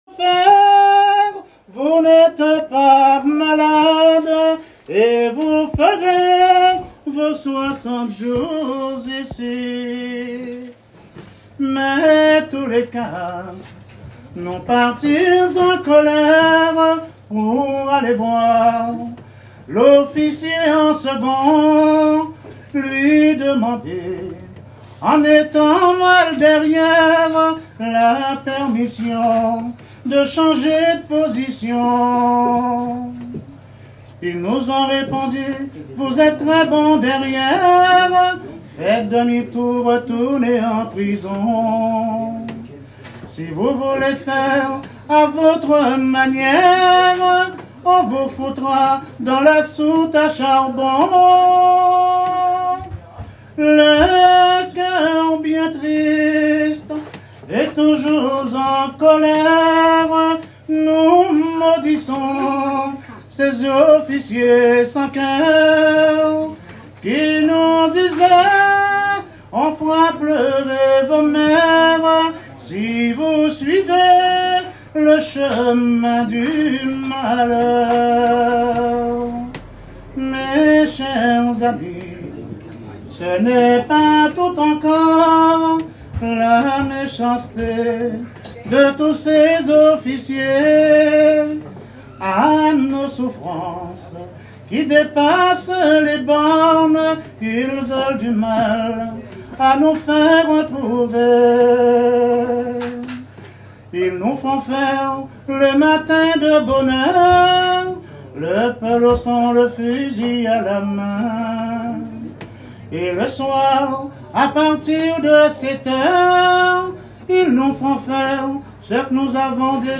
Genre strophique
chansons de traditions orales
Pièce musicale inédite